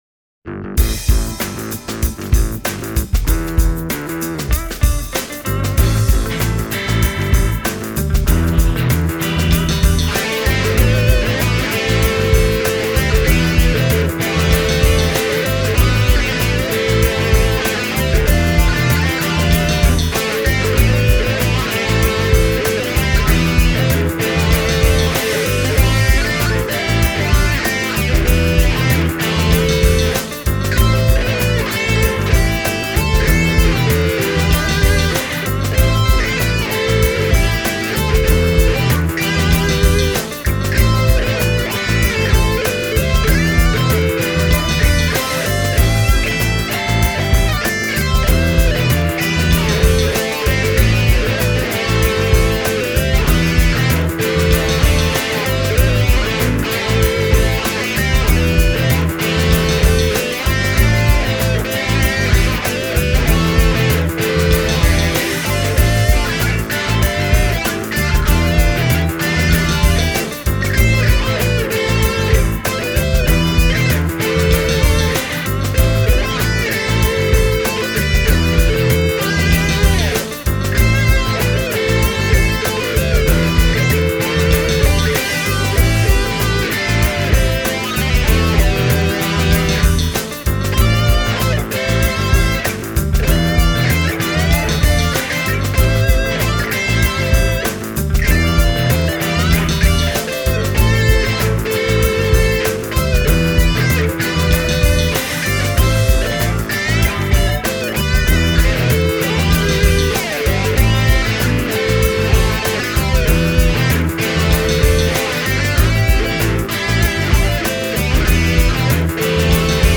Esimerkkikappaleessa on rumpu- ja perkussioraitojen lisäksi ainoastaan MS-60B- ja MS-100BT-pedaalien kautta äänitettyjä basso- (Jazz Bass) ja kitararaitoja (Telecaster):